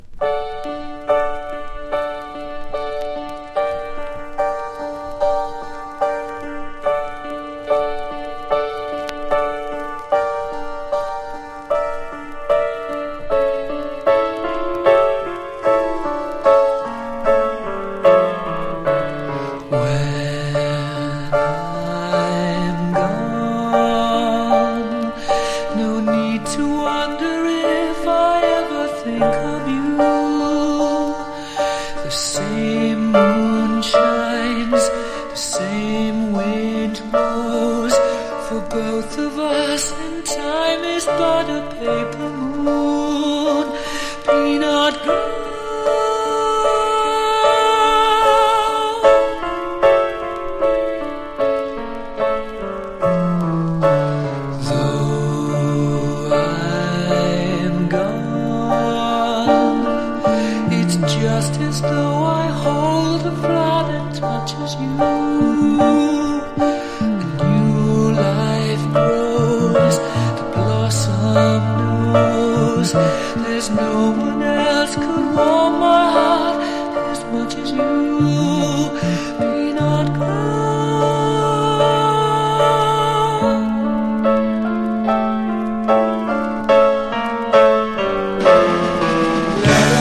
1. 70'S ROCK >